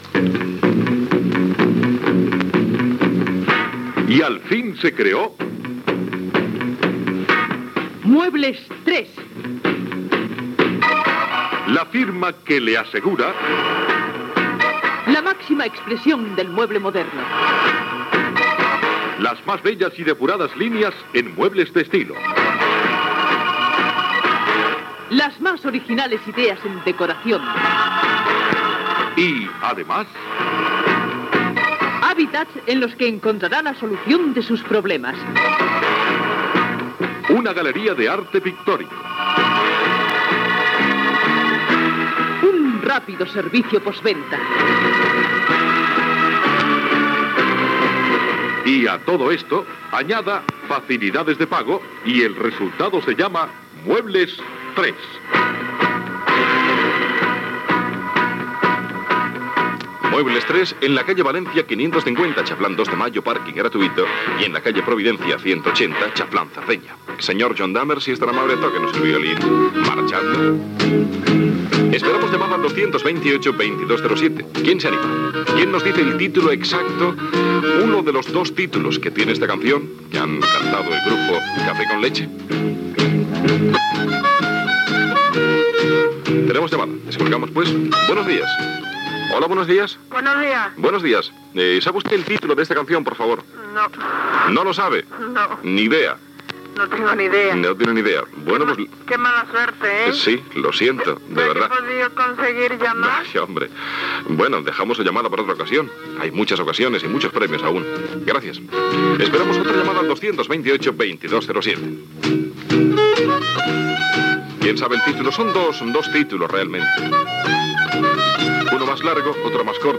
Publicitat, trucades per encertar una cançó, comentari sobre la pel·lícula "El último Tango en París"
Entreteniment